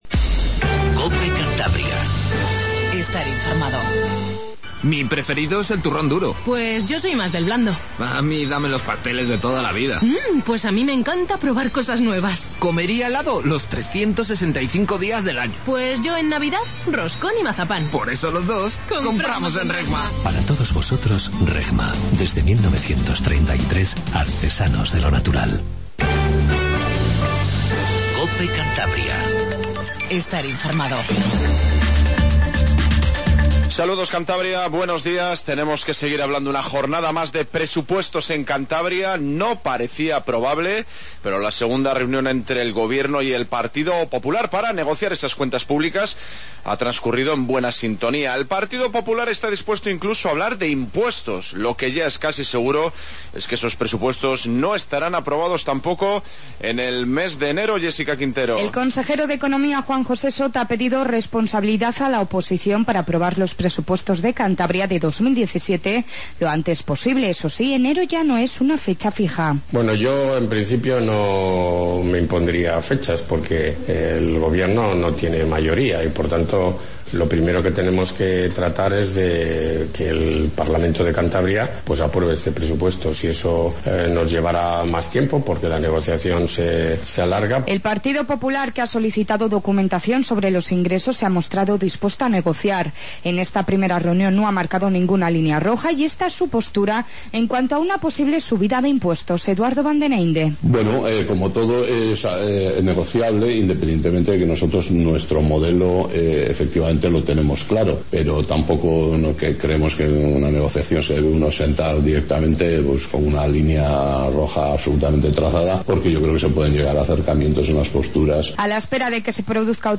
INFORMATIVO MATINAL 07:25